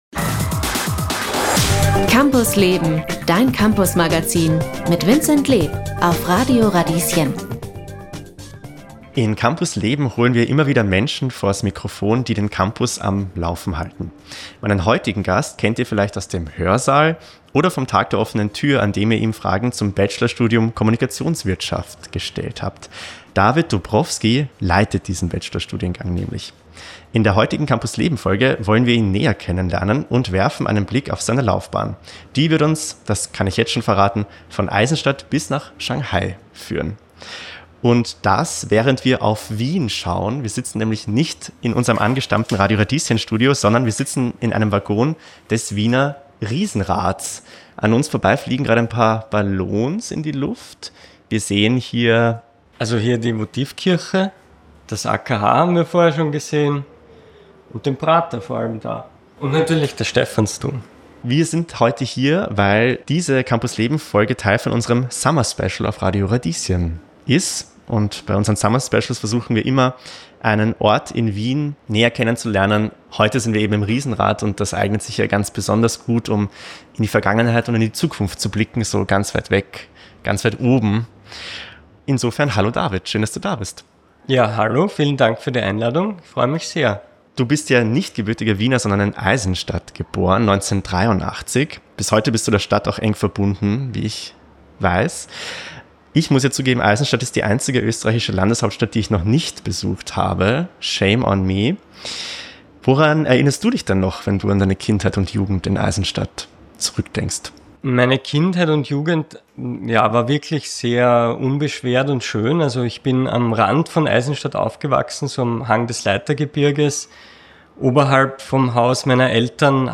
Dieser Podcast ist ein Ausschnitt aus der Campus Leben-Radiosendung vom 25. Juni. Die Sendung ist Teil des Radio Radieschen-Summer Specials 2025 im Wiener Riesenrad.